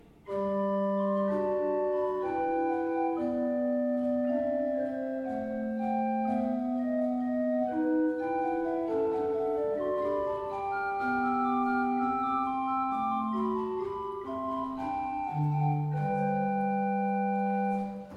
kostel sv. Jana Nepomuckého
Nahrávky varhan:
Vsemina, Kopula minor.mp3